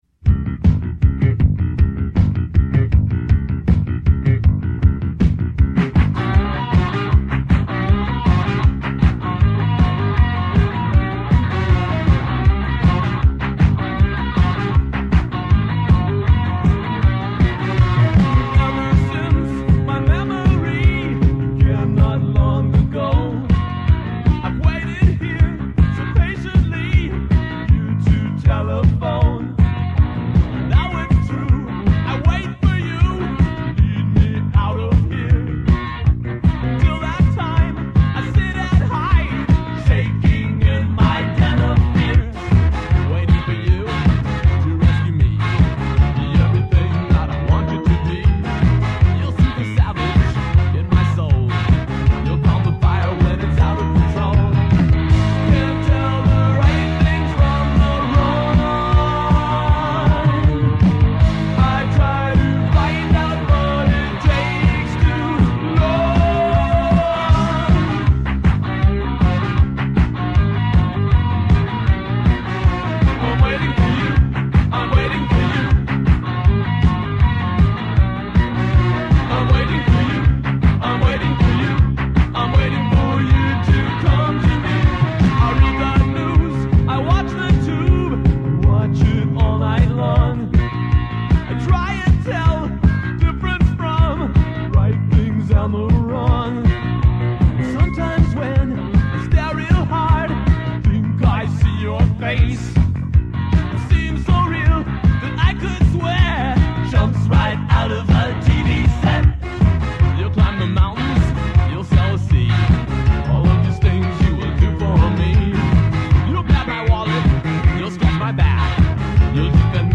One of the best new wave bands of its time